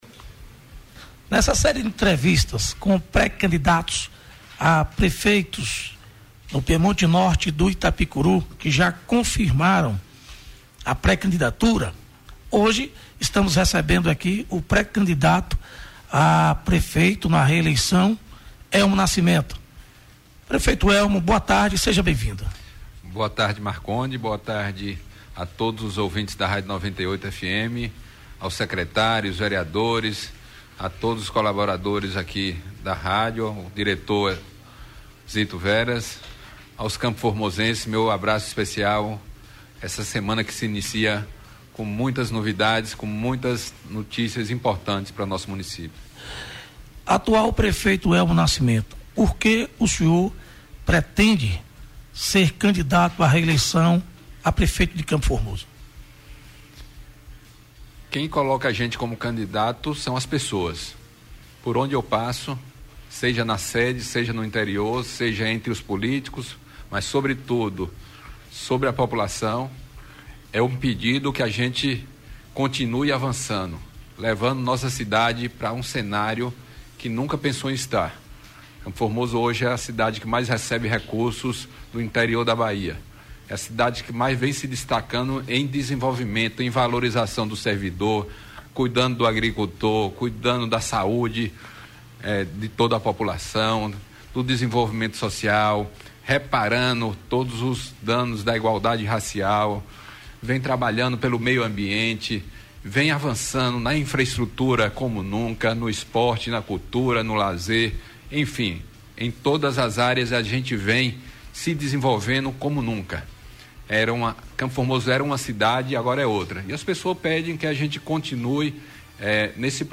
Entrevista ao vivo com Elmo Nascimento, como pré-candidato a reeleição para prefeito de CFormoso